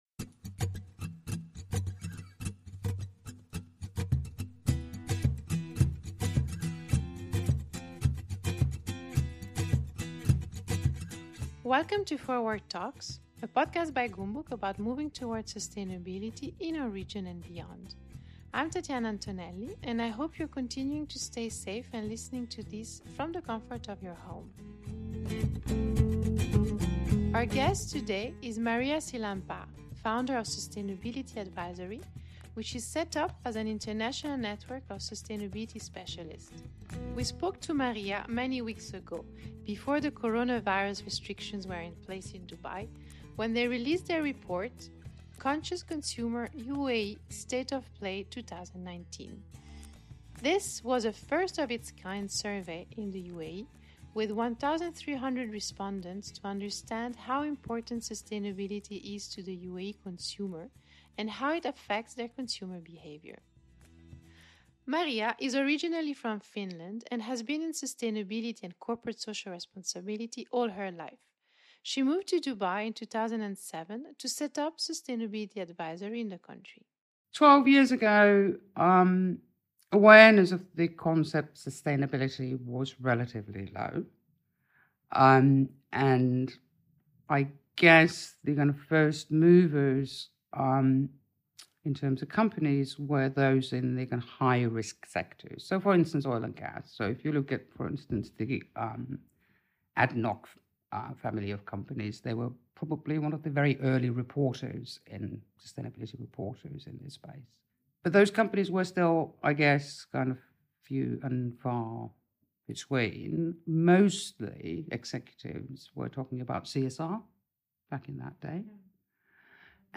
This was a conversation we did much before the restrictions were put in to control the spread of the Novel Coronavirus.